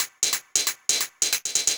Index of /musicradar/ultimate-hihat-samples/135bpm
UHH_ElectroHatC_135-01.wav